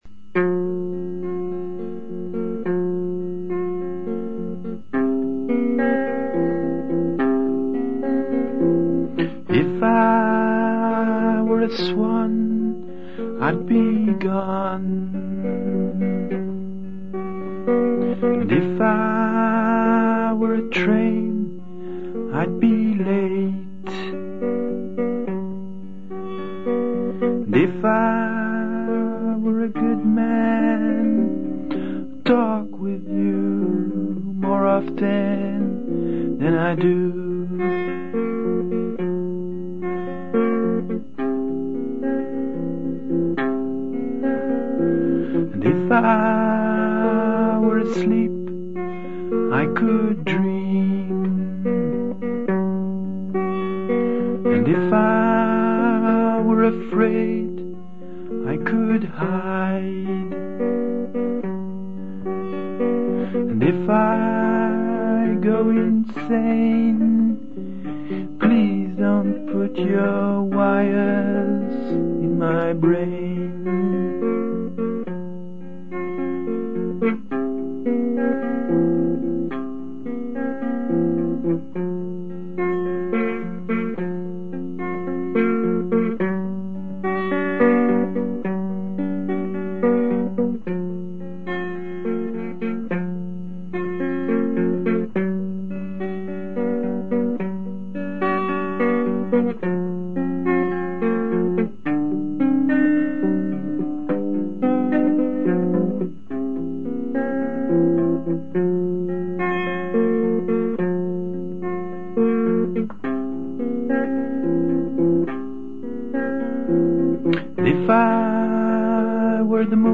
sang one fret higher though, much better.